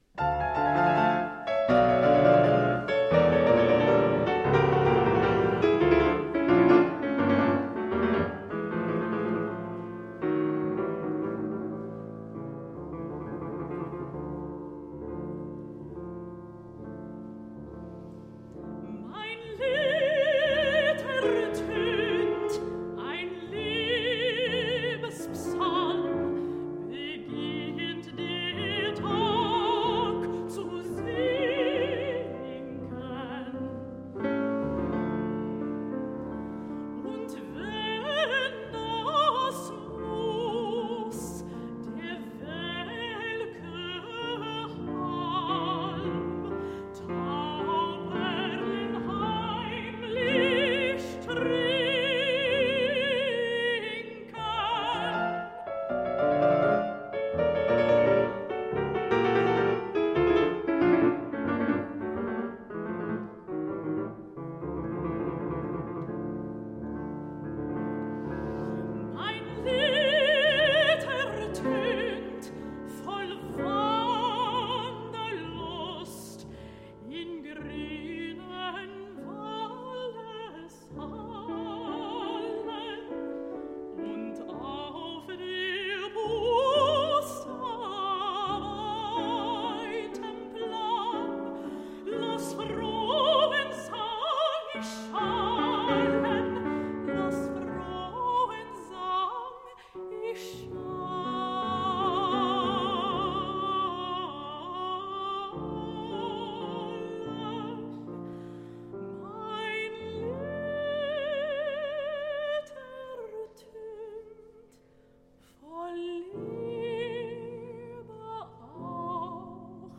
Style: Classical
Audio: Boston - Isabella Stewart Gardner Museum
mezzo-soprano
piano